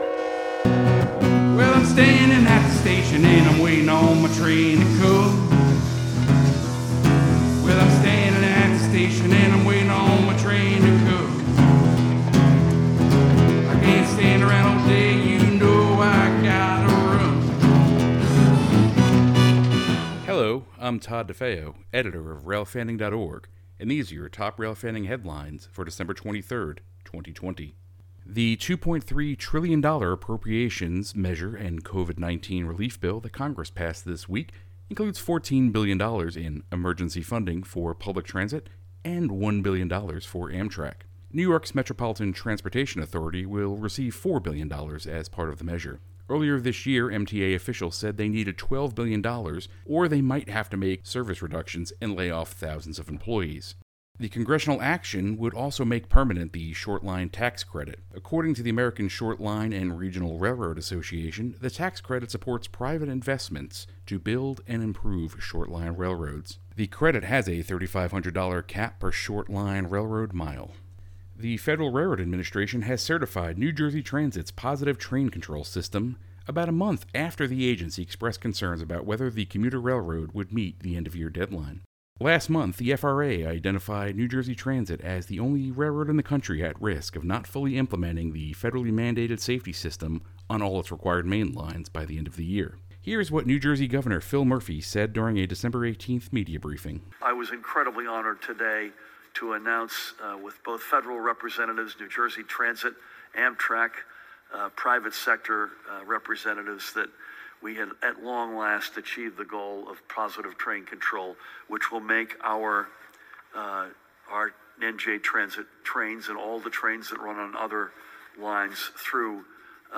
Sound Effects
• Diesel Horn: Recorded at the Southeastern Railway Museum on Nov. 14, 2020.
• Steam Train: 1880s Train, recorded Sept. 12, 2020, in Hill City, South Dakota